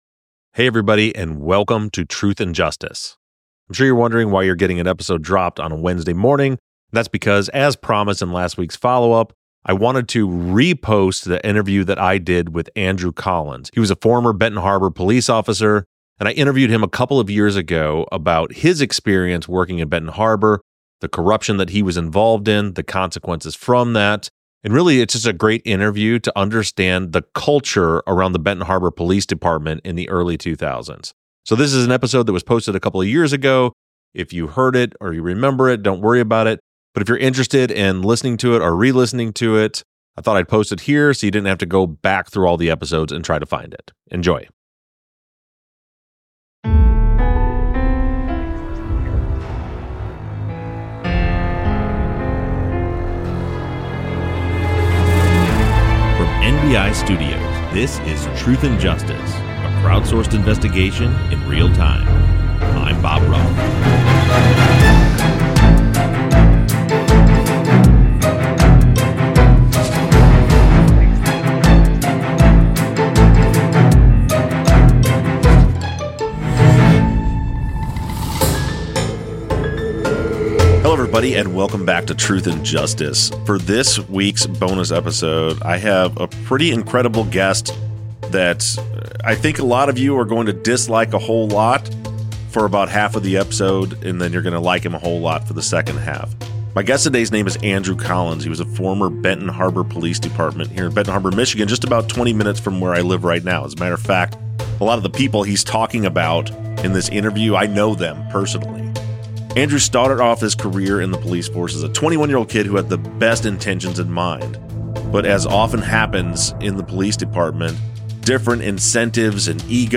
BONUS: Interview w/ a Former Benton Harbor Cop